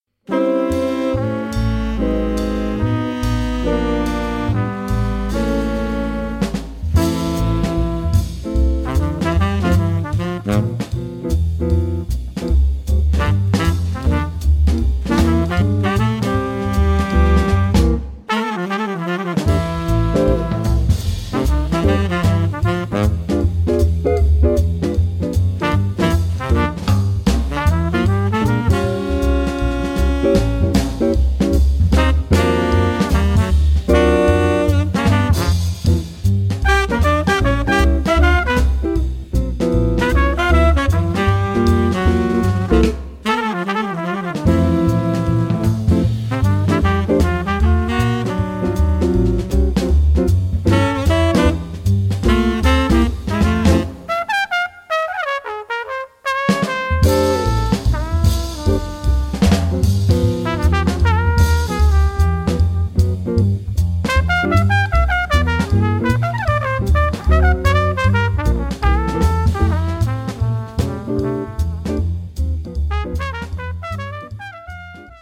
Contrebasse
Guitare
Ténor sax
Trompette
Batterie